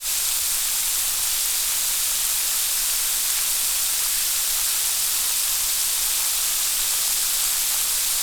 Water, Spray, Leak From Connection, Constant SND35349.wav